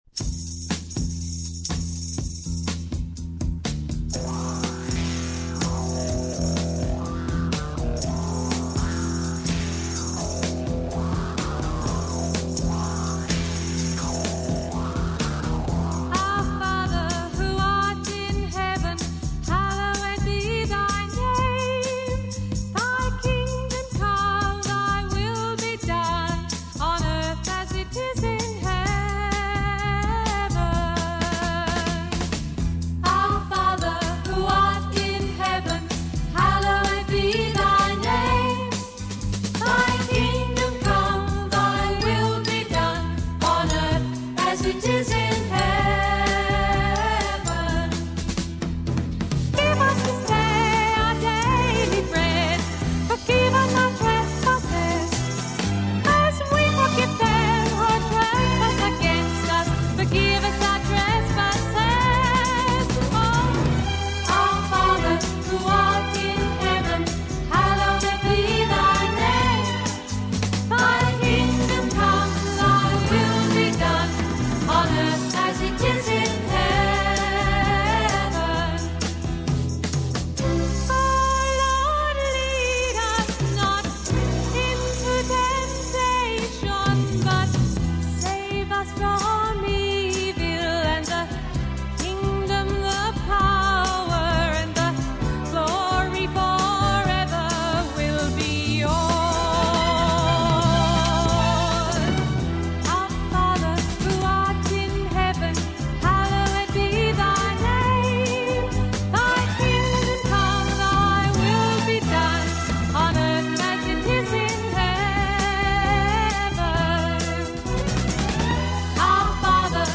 Вот тут качество отличное